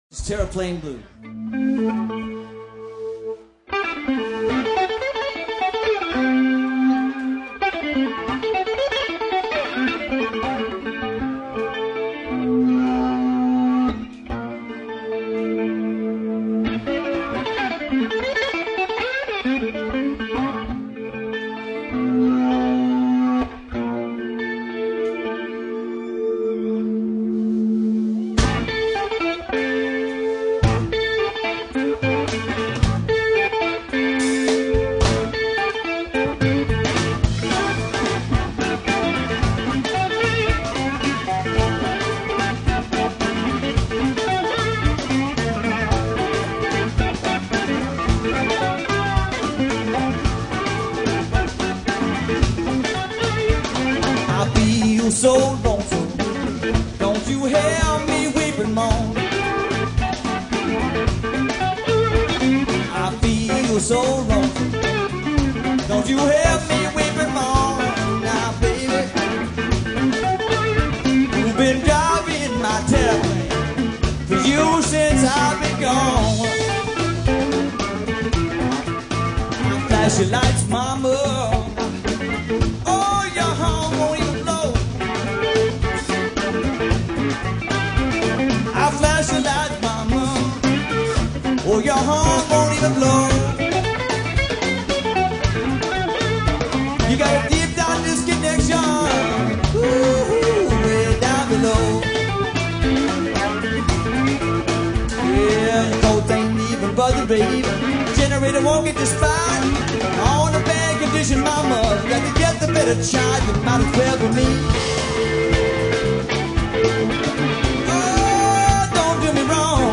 And this is a live recording!